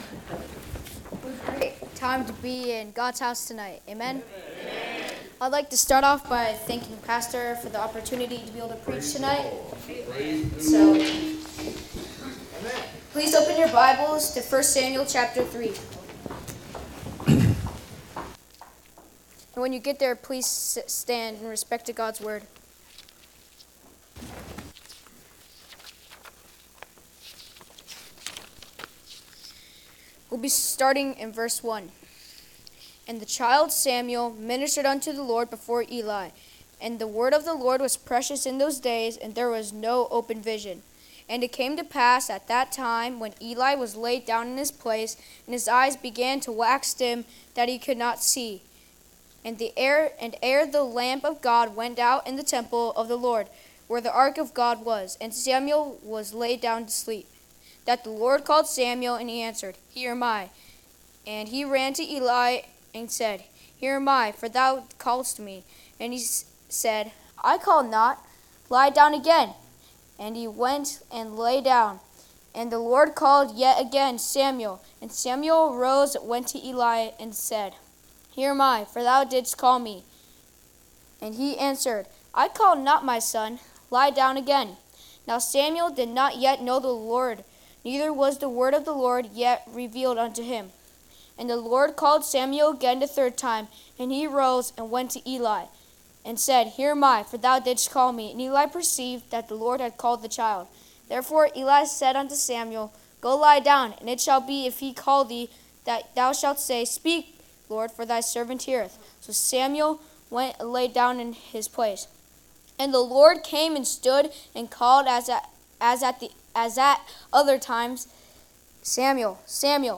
2025 Guest Preacher http